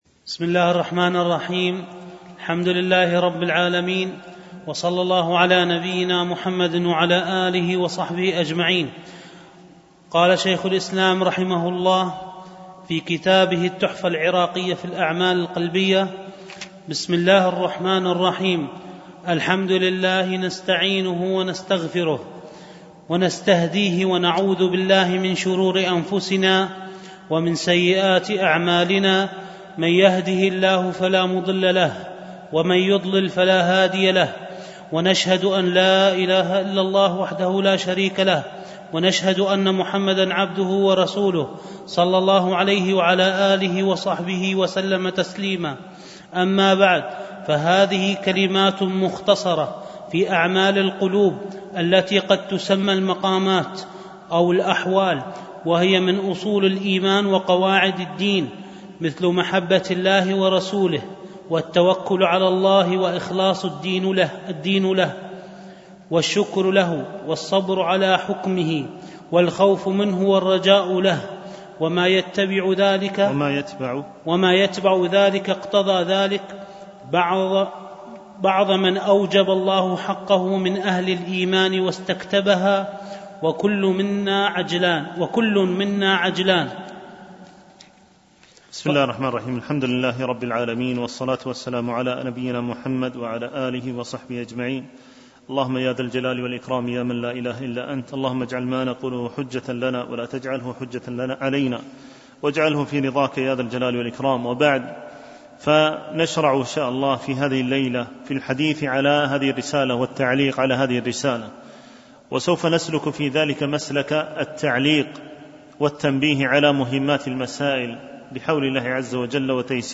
دروس مسجد عائشة